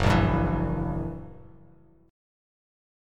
F#7b9 chord